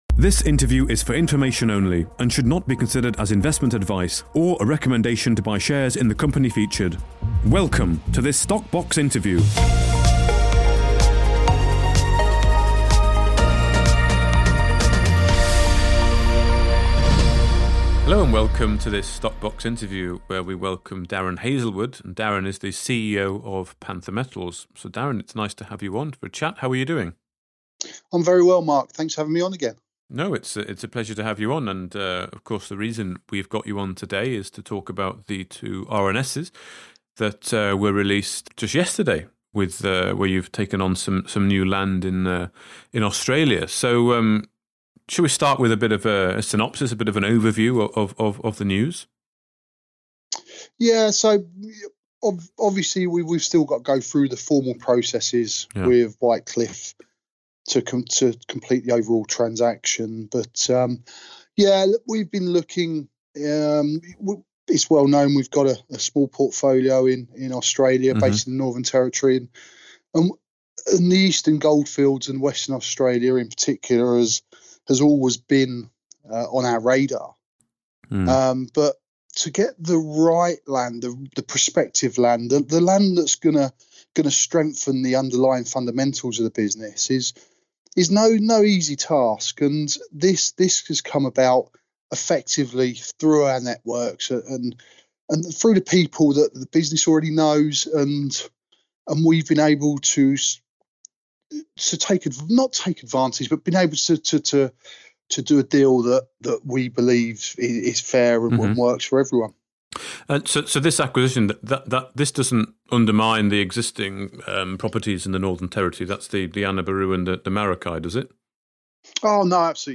StockBox Podcasts / INTERVIEW